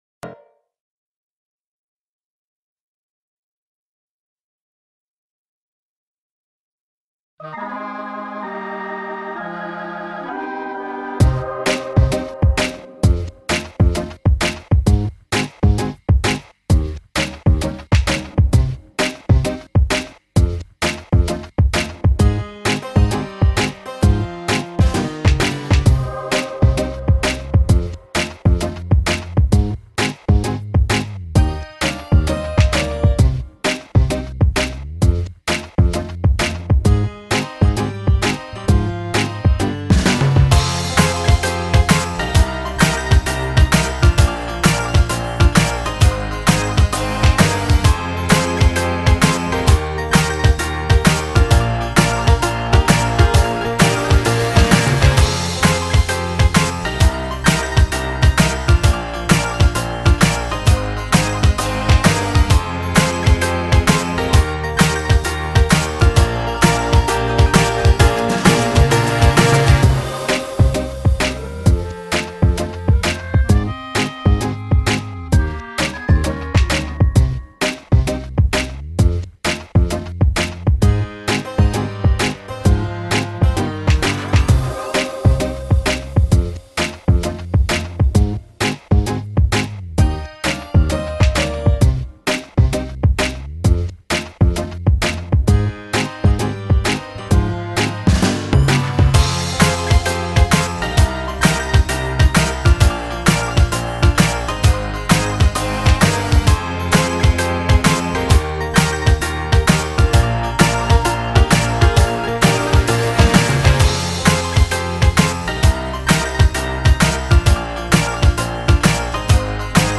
Pop Instrumental